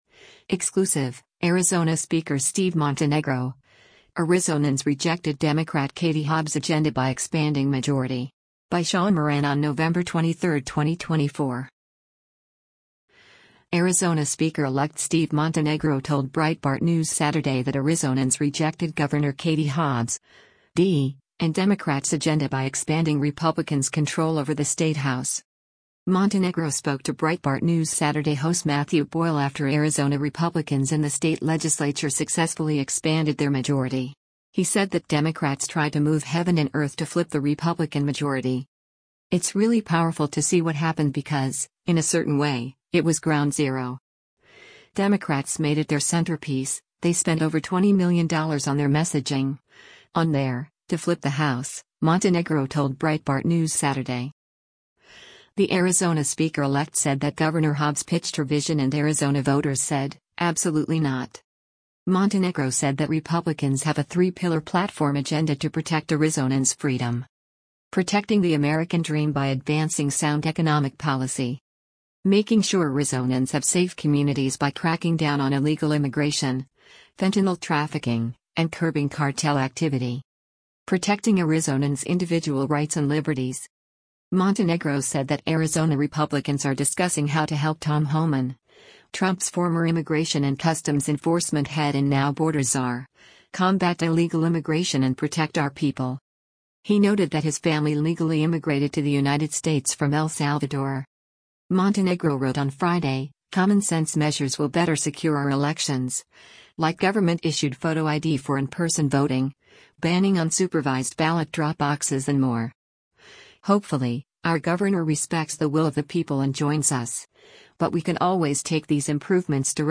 Arizona Speaker-elect Steve Montenegro told Breitbart News Saturday that Arizonans rejected Gov. Katie Hobbs (D) and Democrats’ agenda by expanding Republicans’ control over the state House.
Breitbart News Saturday airs on SiriusXM Patriot 125 from 10:00 A.M. to 1:00 P.M. Eastern.